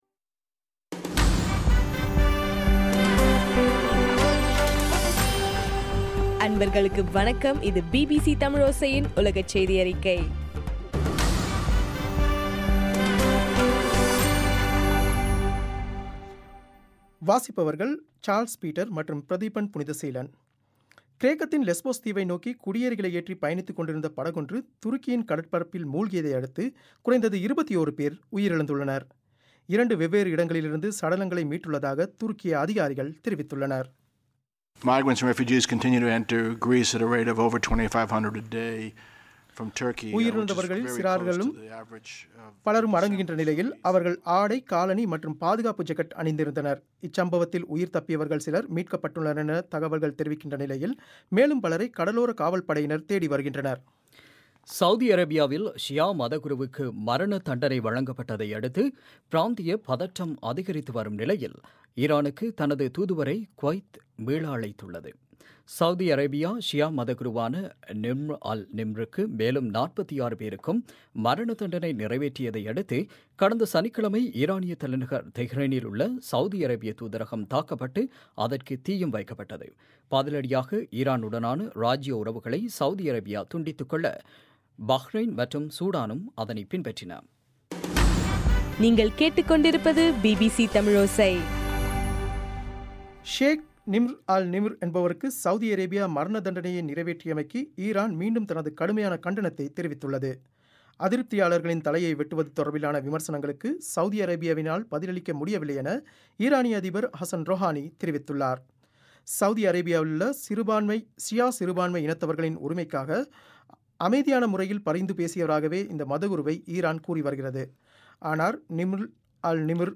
இன்றைய (ஜனவரி 5) பிபிசி தமிழோசை செய்தியறிக்கை